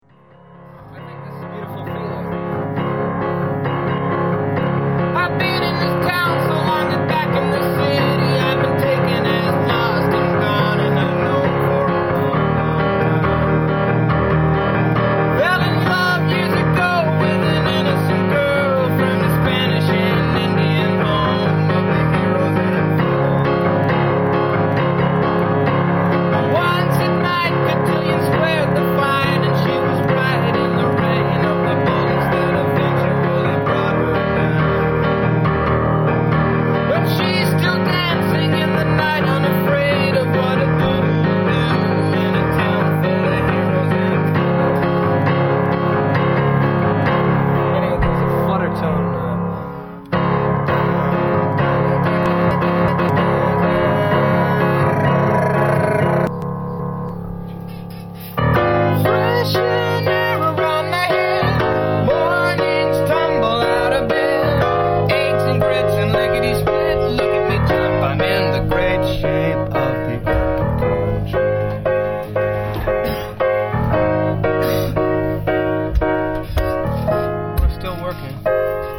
Today we have a demo of the song